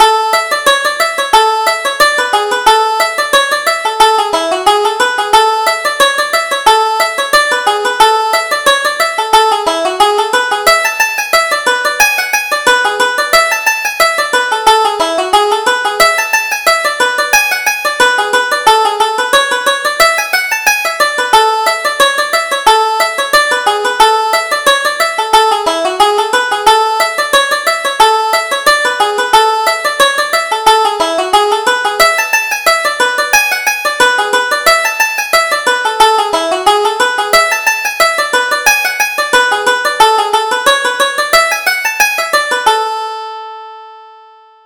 Reel: The Ivy Leaf - 2nd Setting